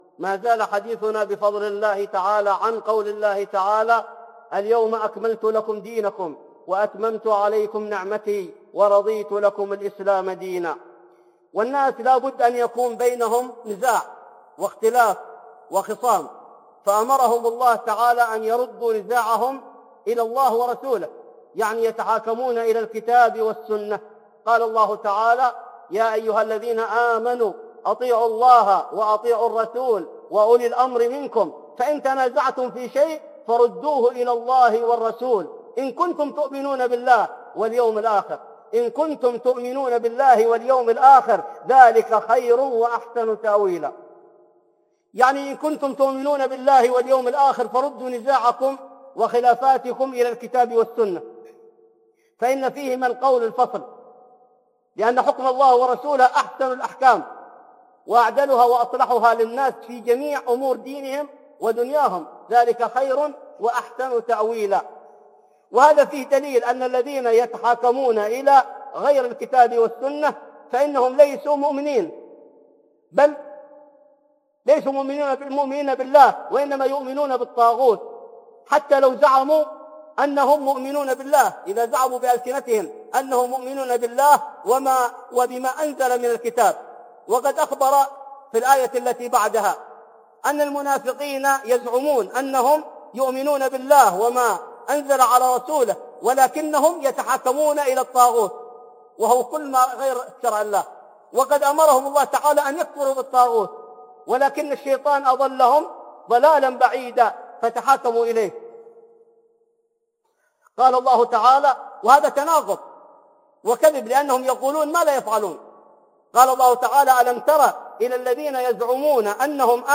وجوب التحاكم إلى الشرع عند الاختلاف - خطب